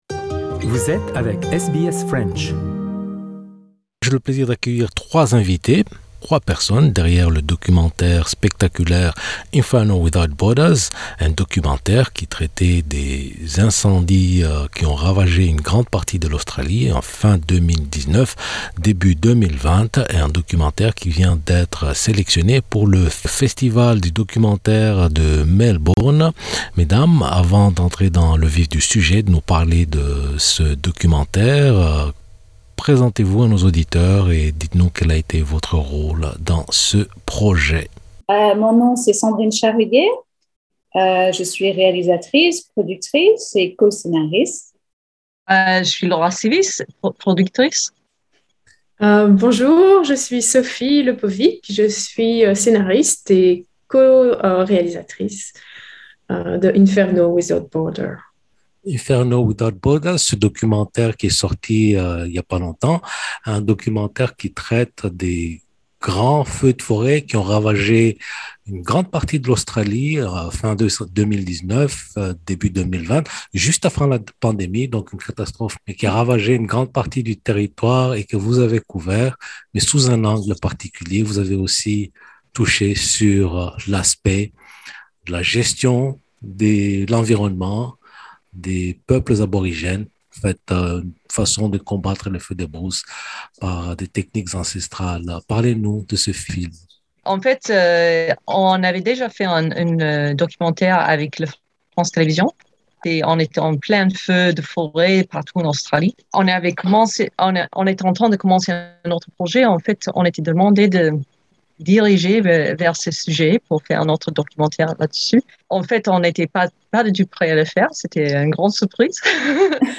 Interview banner Inferno Without Borders Source